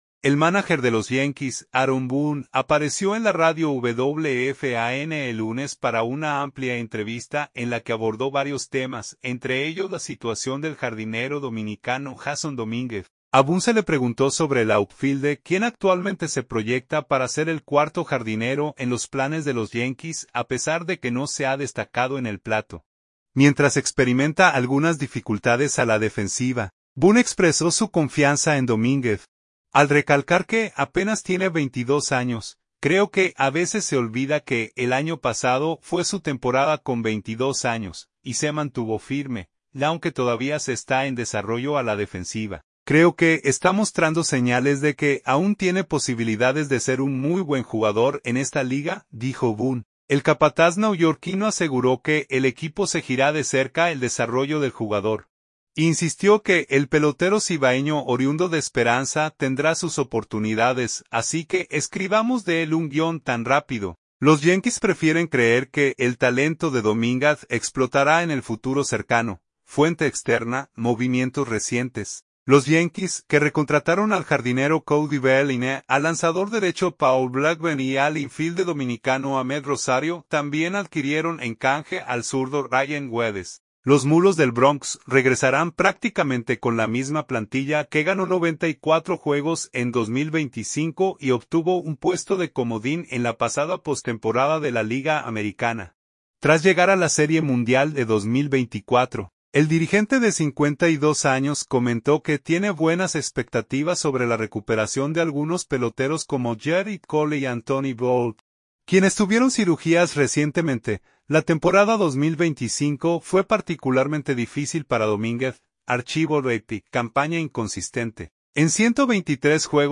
En una entrevista radial, el dirigente neuyorquino recordó que Domínguez apenas tiene 22 años
El mánager de los Yankees, Aaron Boone, apareció en la radio WFAN el lunes para una amplia entrevista en la que abordó varios temas, entre ellos la situación del jardinero dominicano Jasson Domínguez.